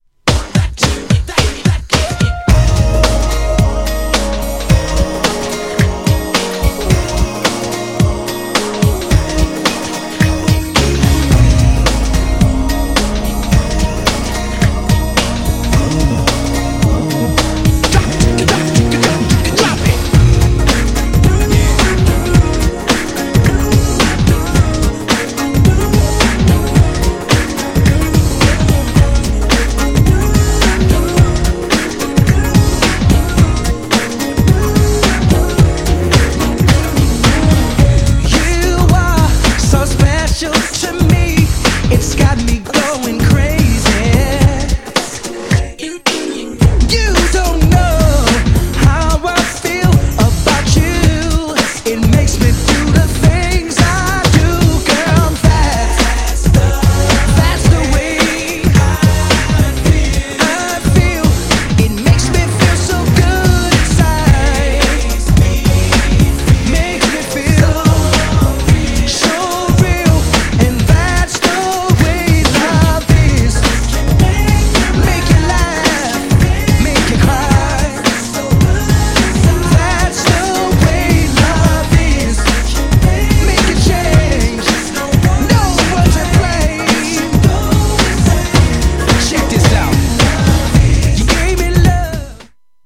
GENRE R&B
BPM 106〜110BPM
# GROOVYなR&B
# NEW_JACK
# タイトなビートがイイ # ダンサブルR&B # 男性VOCAL_R&B